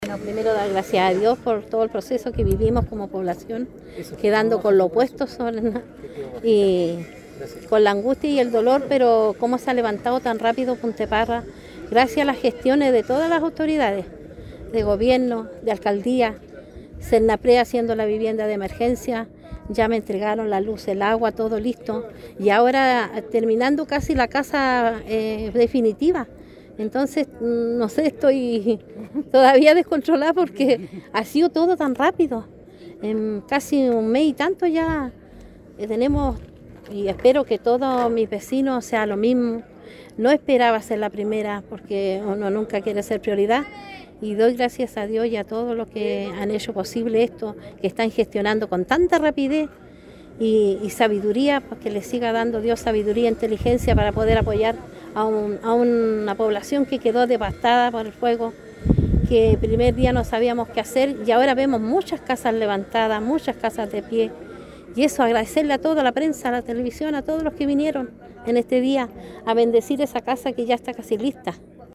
“Quedamos con mucha angustia y con dolor después de lo vivido, pero lo rápido que se ha levantado Punta de Parra es gracias a las gestiones de todas las autoridades”, explicó emocionada.